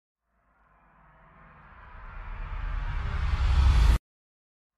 Звук воспоминаний или флешбеков, когда человек в кино что-то вспоминает